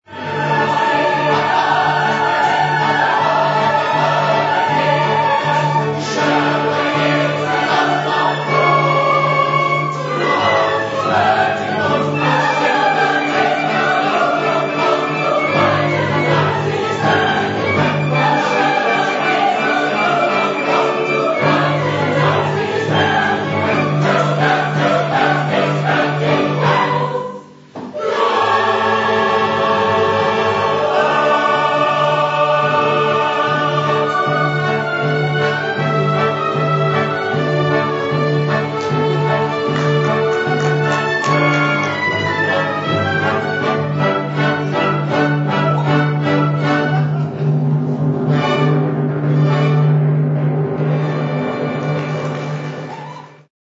making live recordings of the society's productions.